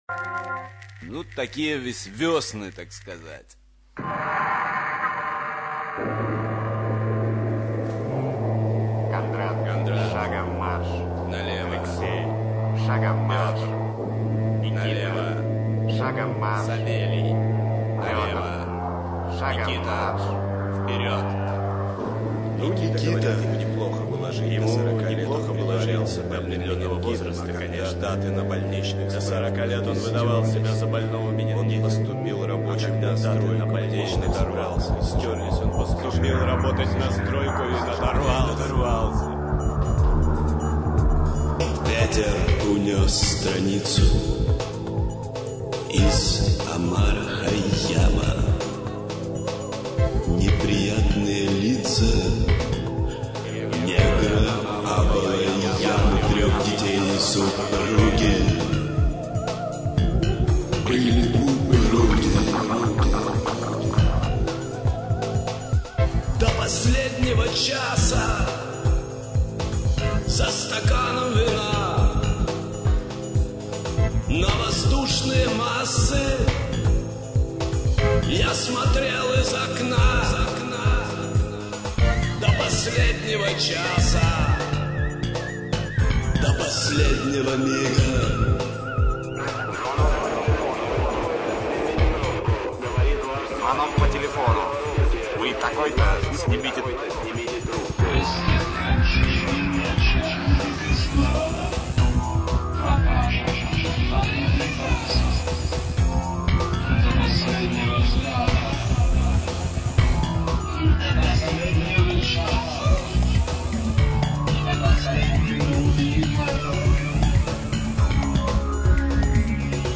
Вокал
синтезатор